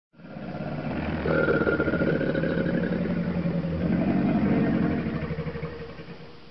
water_life_croc1.ogg